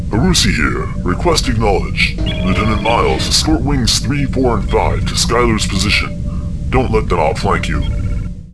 Human Male, Age 55